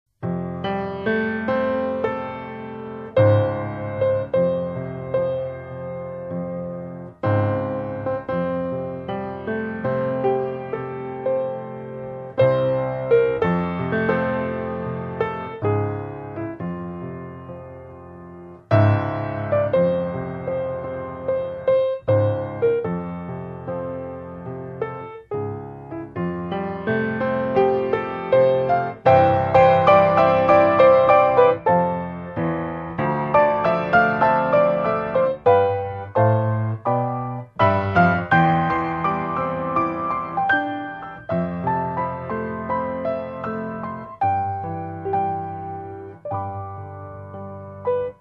Piano Players - No Vocal - Piano Music Elegance